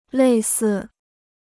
类似 (lèi sì): similar; analogous.